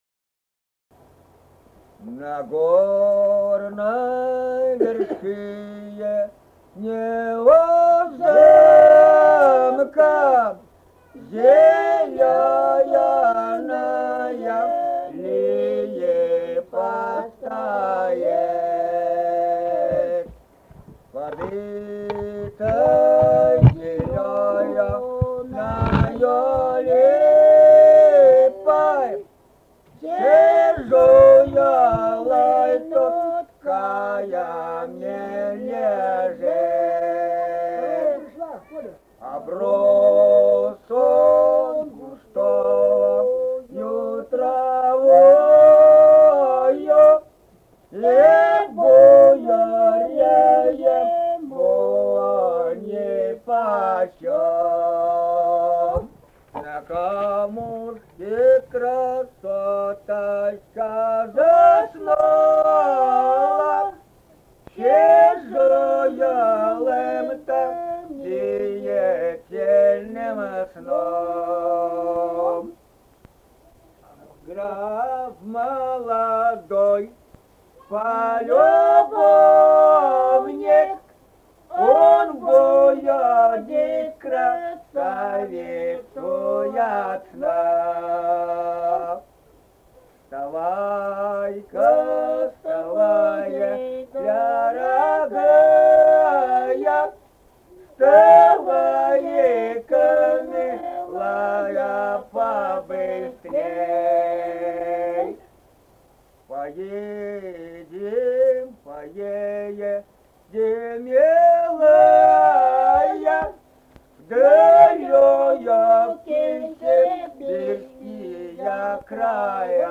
Республика Казахстан, Восточно-Казахстанская обл., Катон-Карагайский р-н, с. Коробиха, июль 1978.